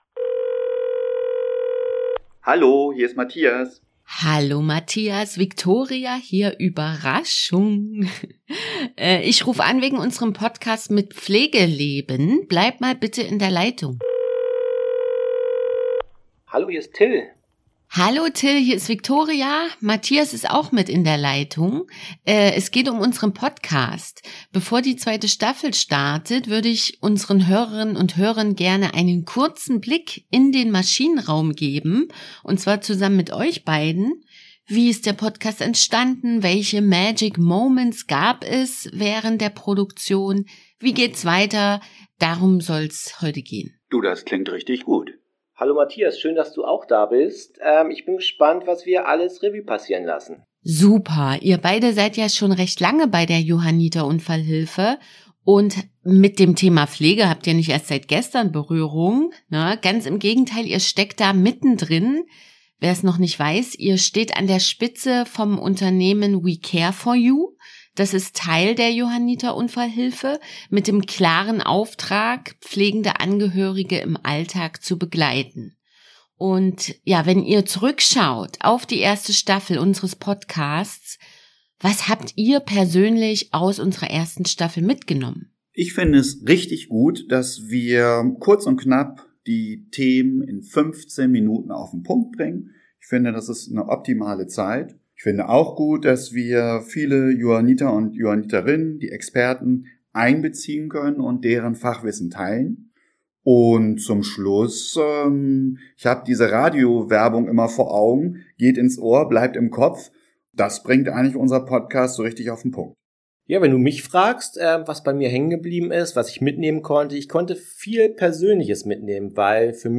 In dieser Bonusfolge telefoniert